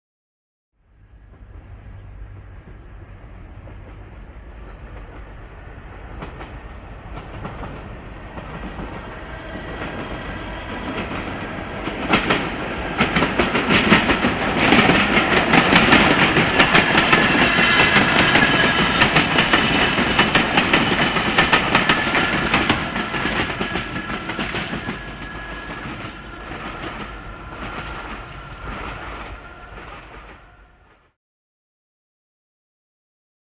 Звук проезжающей мимо электрички